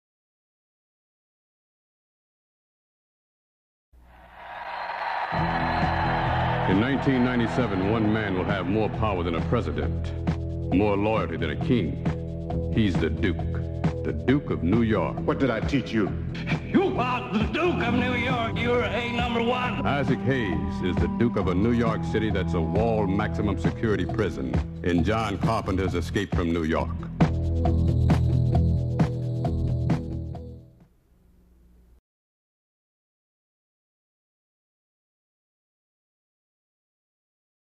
Radio Spots
Escape_From_New_York_Radio_Spot.mp3